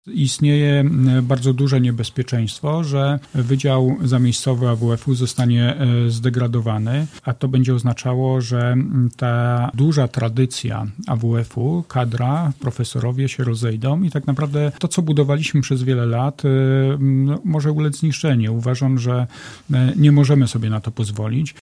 Podczas sesji radni zapoznają się z wynikami ostatnich rozmów prezydenta Gorzowa z rektorem AWF w Poznaniu. Jeśli miasto nie dojdzie do porozumienia z uczelnią, gorzowski wydział może ulec zniszczeniu – mówi wiceprzewodniczący rady Miasta z PO Robert Surowiec: